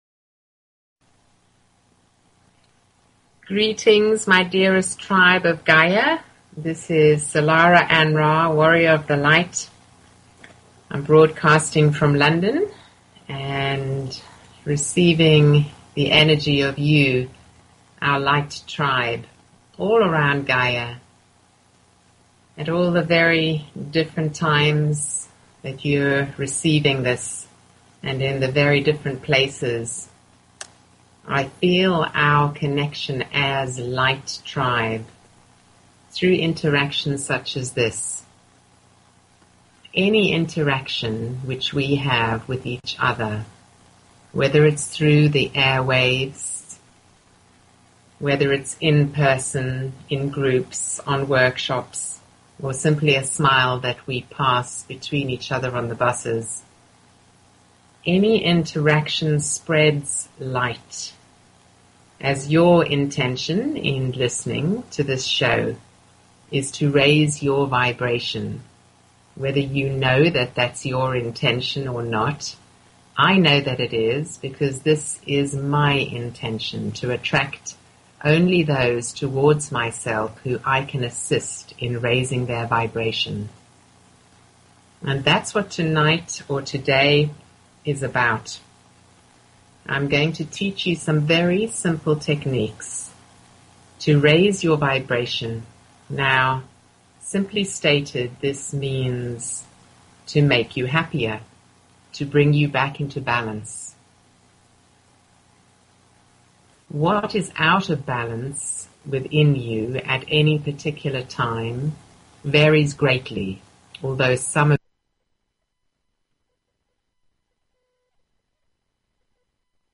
Talk Show Episode, Audio Podcast, Illumination_from_the_Councils_of_Light and Courtesy of BBS Radio on , show guests , about , categorized as